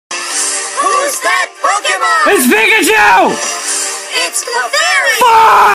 Pikachu Sound Effects MP3 Download Free - Quick Sounds